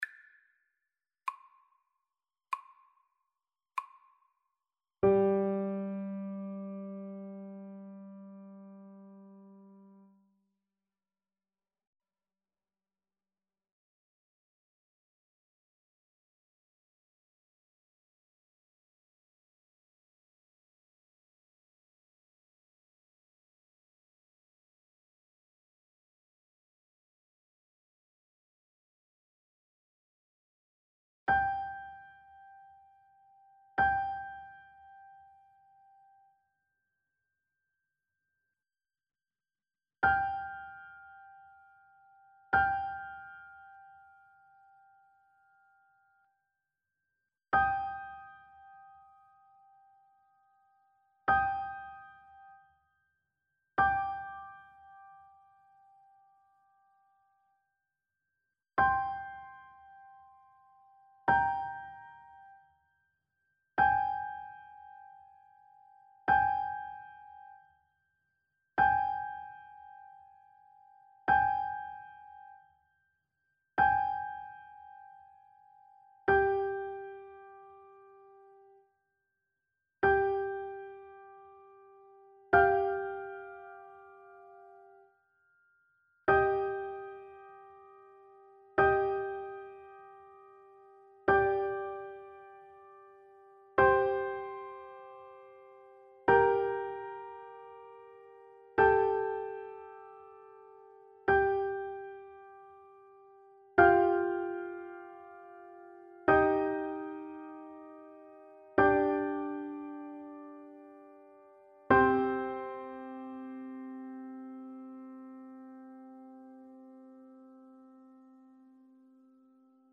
Lent =48
Classical (View more Classical Trumpet Music)